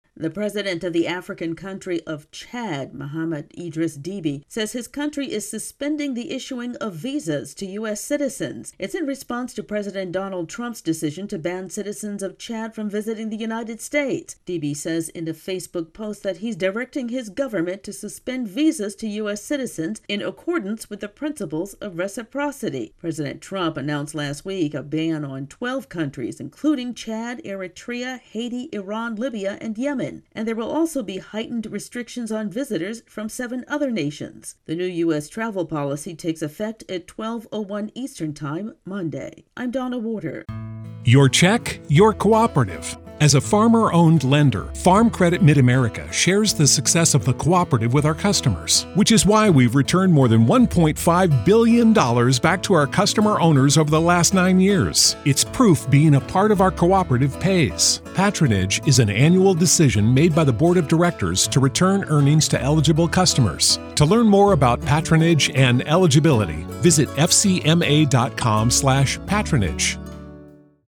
The African nation of Chad is firing back following the United States' travel ban. AP correspondent